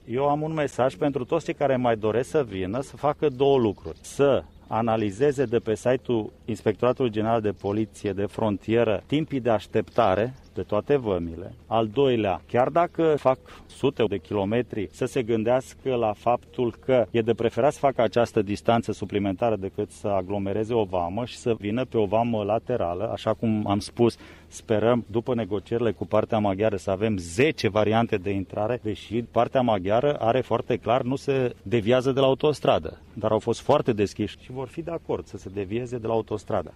Ministrul de Interne s-a aflat ieri la Nãdlac, unde, în ultimele zile oamenii au așteptat chiar și 12 ore pentru a intra în țarã. El a spus cã partea maghiarã a convenit sã deschidã și alte puncte de trecere a frontierei: